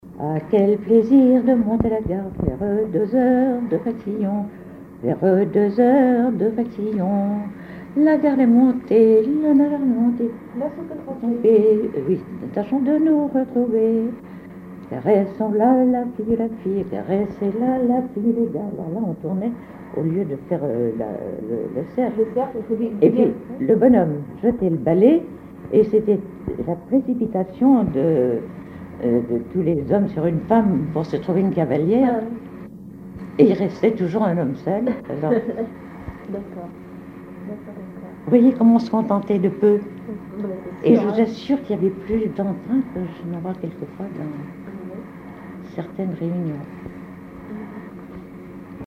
danse-jeu : danse du balais
Genre énumérative
Pièce musicale inédite